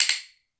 拍子木っぽい音２
sounds_Hyoshigi2.mp3